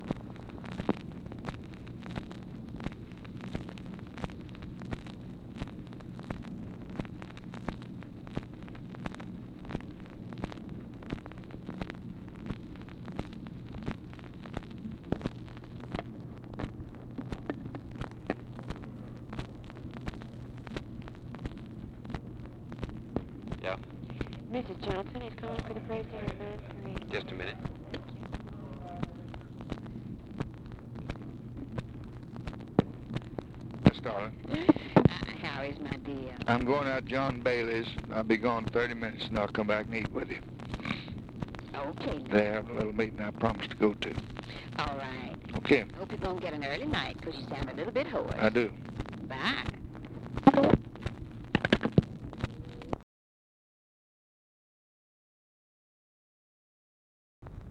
LBJ SAYS HE IS GOING TO RECEPTION AT JOHN BAILEY'S AND WILL RETURN FOR DINNER; MRS. JOHNSON TELLS LBJ SHE HOPES HE MAKES IT AN EARLY NIGHT BECAUSE HE IS HOARSE
Conversation with LADY BIRD JOHNSON, March 19, 1964